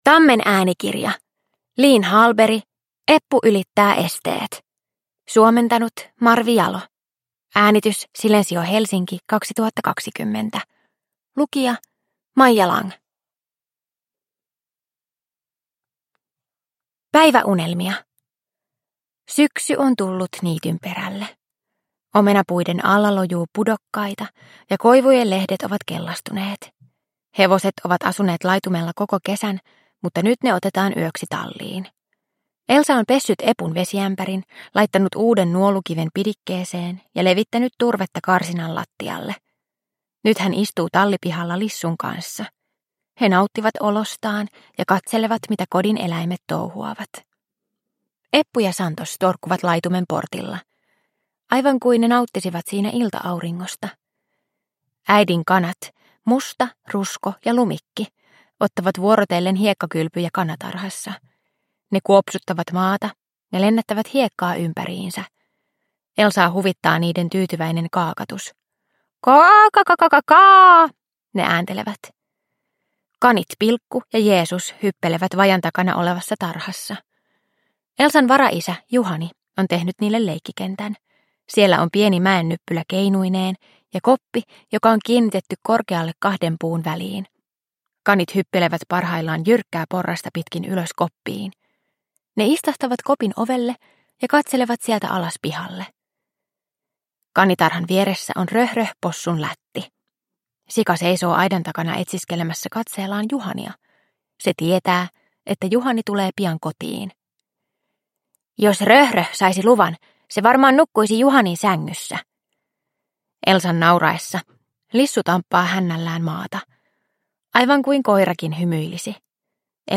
Eppu ylittää esteet – Ljudbok – Laddas ner